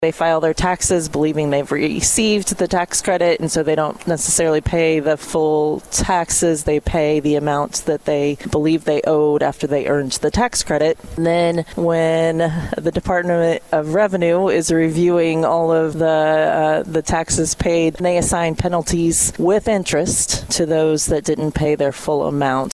Jefferson City, Mo. (KFMO) - Parkland voters are more informed on the legislative agenda of our local legislators after our annual legislative broadcast from Jefferson City Tuesday.
Our first interview during the program was with Becky Laubinger, the State Representative of the 117th District. Laubinger talked about her bill dealing with charitable tax credits obtained by individuals and businesses and how they could receive penalties for unknowingly filing incorrect tax amounts once the state reaches its cap on tax credits.